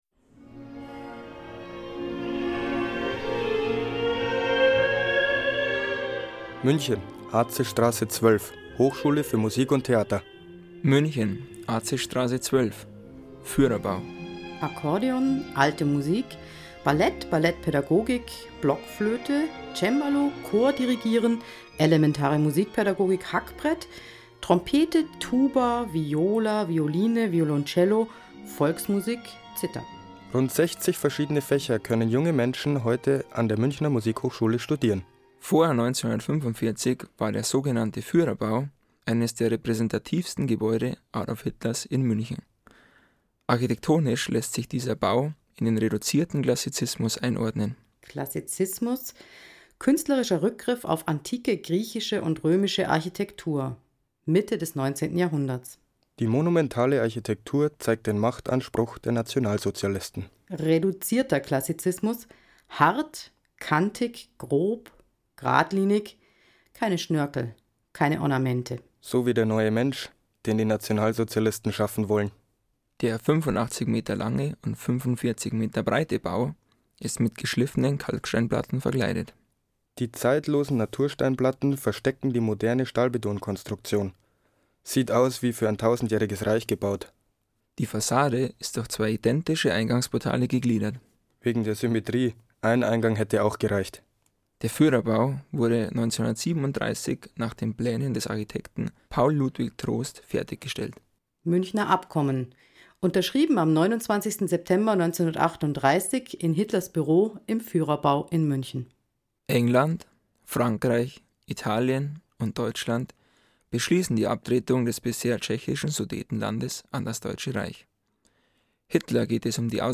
Sechs Schülergruppen produzierten Audioguides und konnten dabei